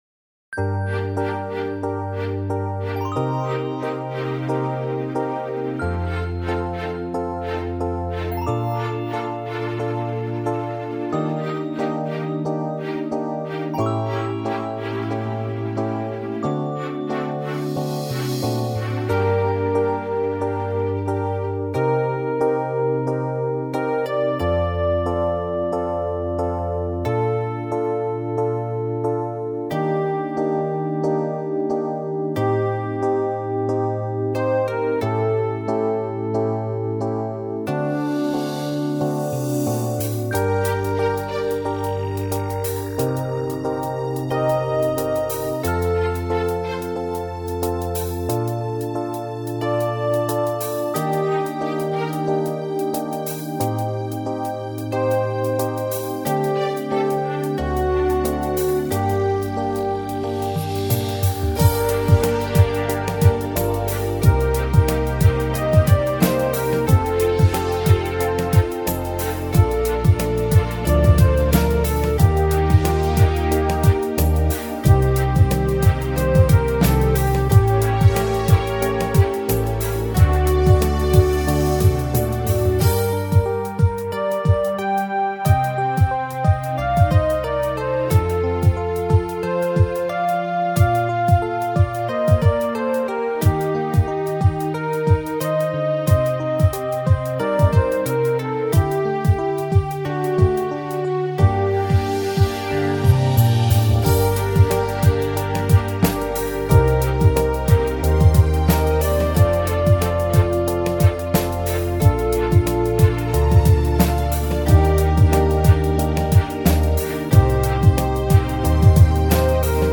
remix